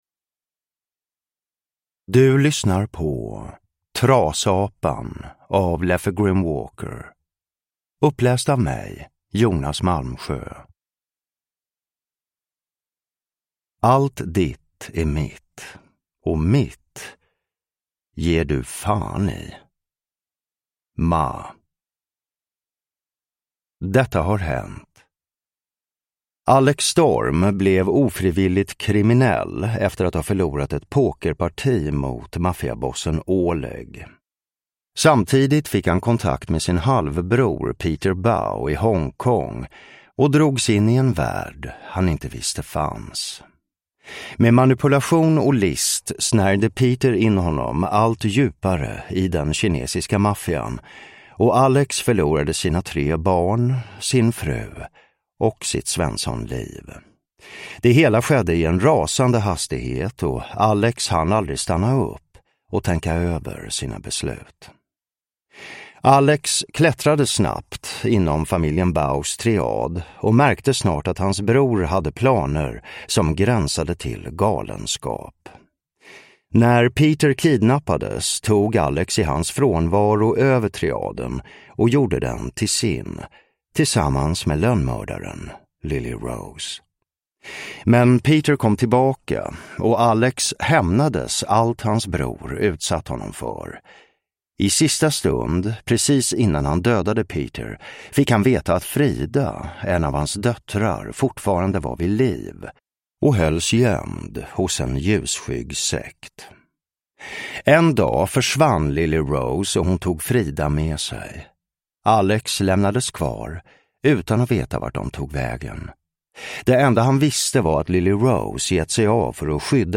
Trasapan (ljudbok) av Leffe Grimwalker | Bokon